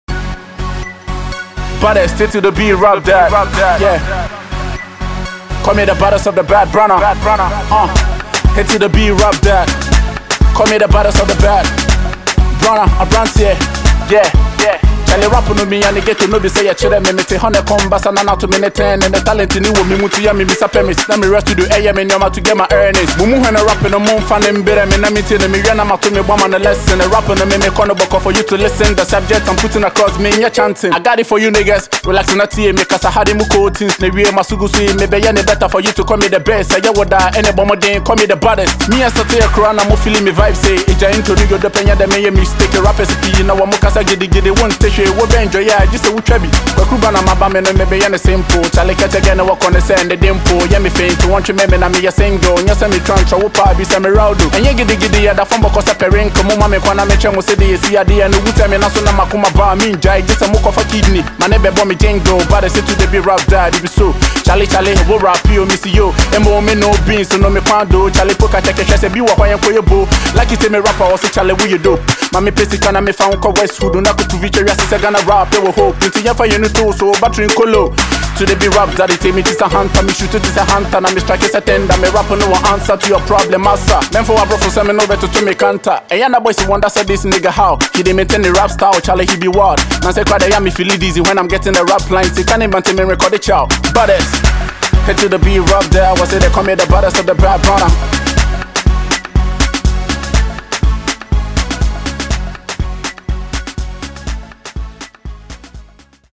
an Afrobeat song